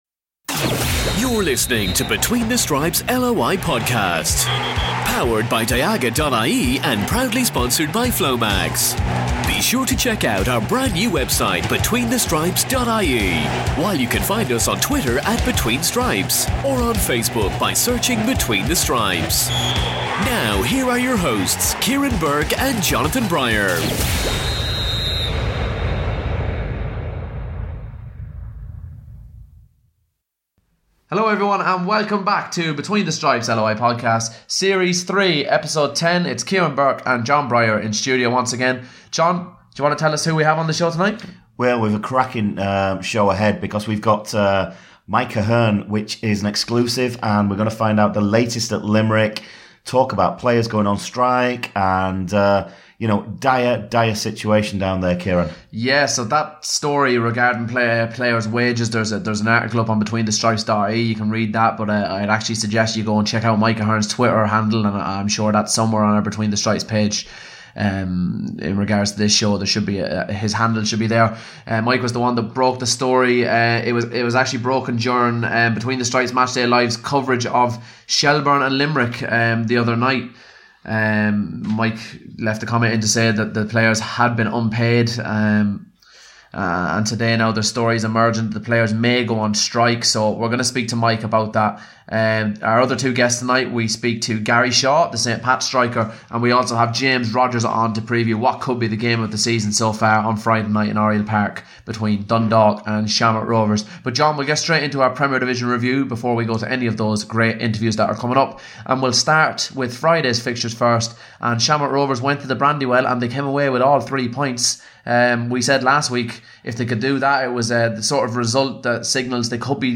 Somehow we've managed to cram in two sets of Premier Division reviews, a First Division review and both divisions previews into this weeks show alongside three cracking interviews.